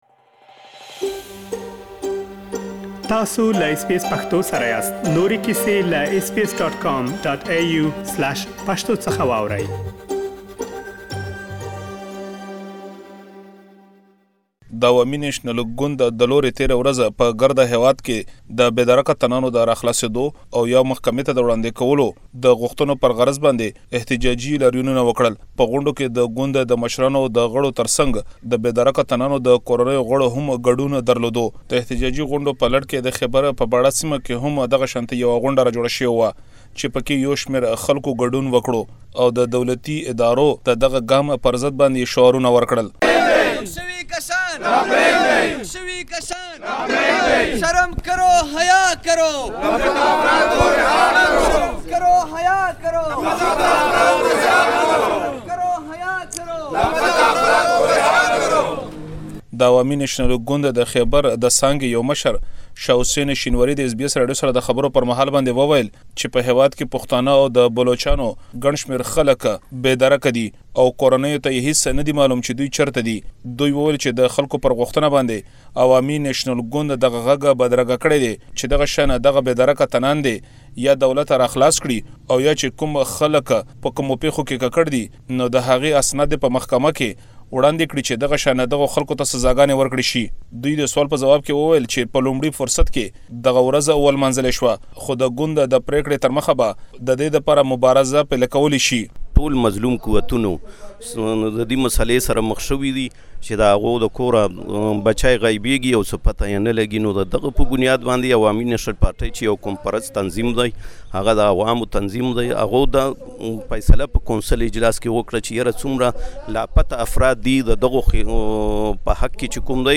ځينو پښتنو له اس بي اس پښتو سره خبرو دوران کې وويل، د دوی ځوانان د امنيتي کسانو لخوا له کوره وړل کيږي بيا دوی محکمو ته نه وړاندې کيږي او کلونو مودې لپاره لا درکه دي.